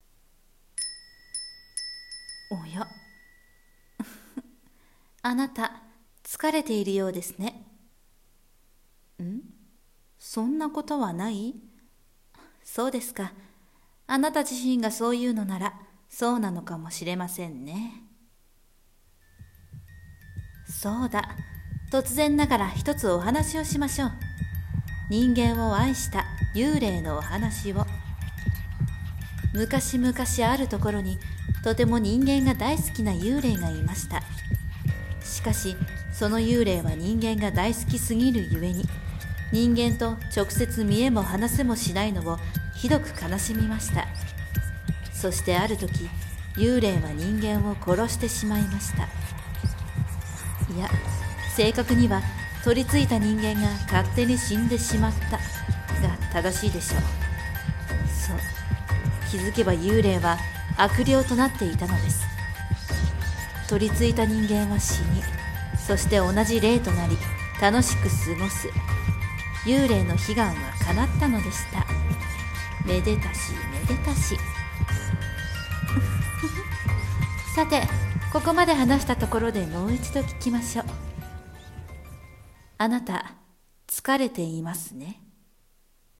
【声劇】あなた、つかれていますね？